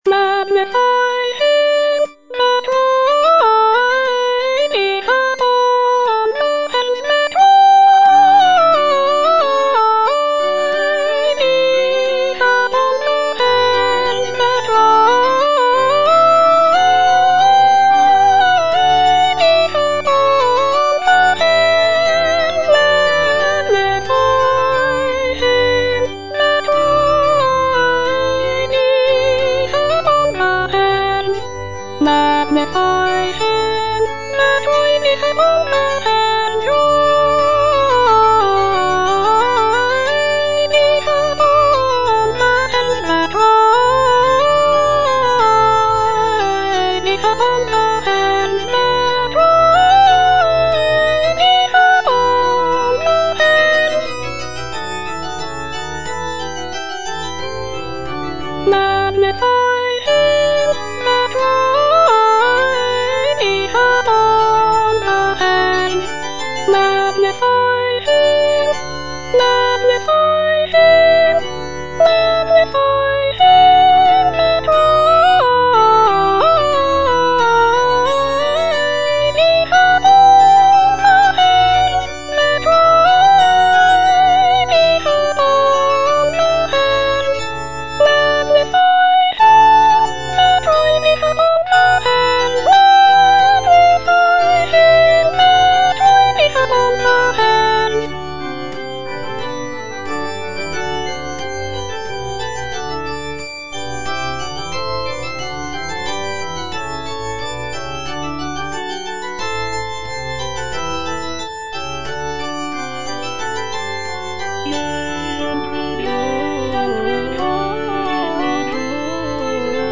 Soprano (Voice with metronome) Ads stop
sacred choral work